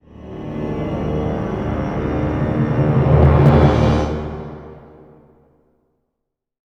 Index of /90_sSampleCDs/Best Service ProSamples vol.33 - Orchestral Loops [AKAI] 1CD/Partition C/CRESCENDOS